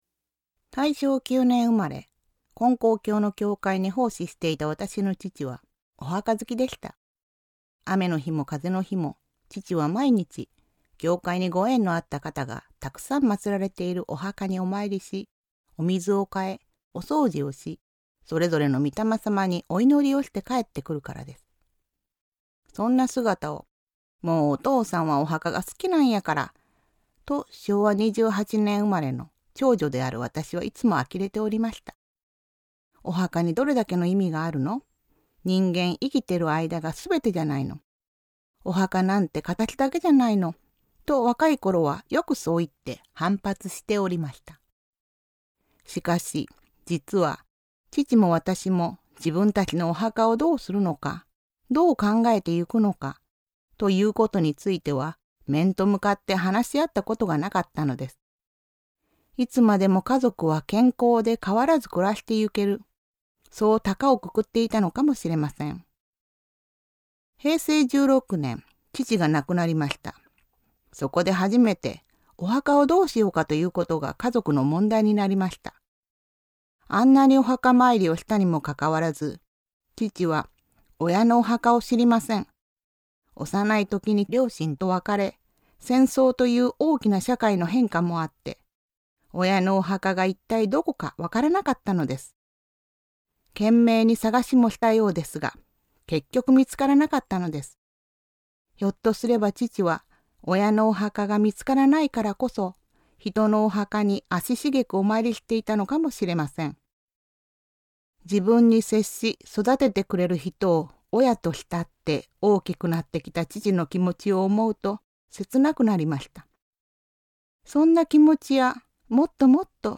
●先生のおはなし